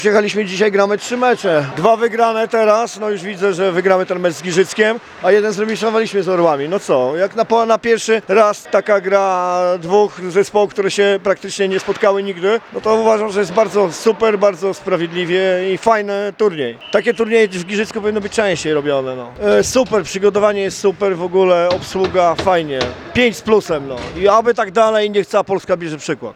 Zawodnicy połączonych sił STH Siedlce oraz Przasnysza również nie kryli zadowolenia z rozegranych meczy.